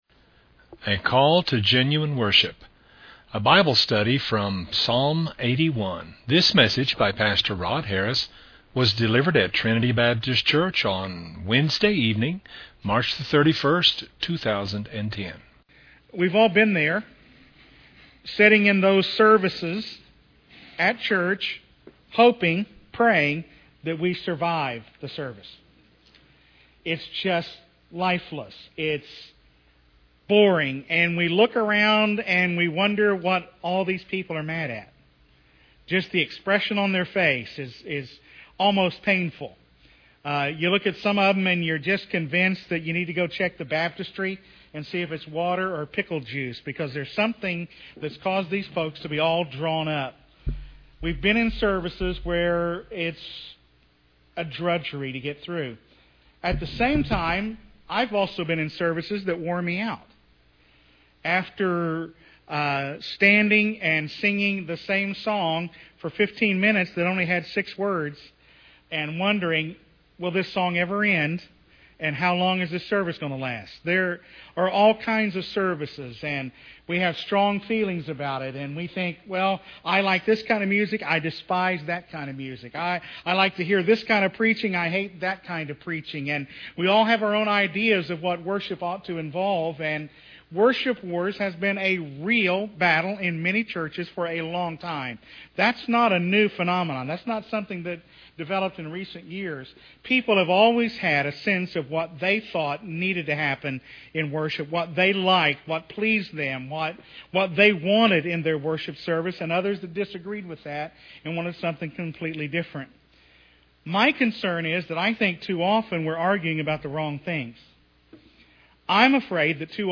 A brief Bible study from Psalm 81.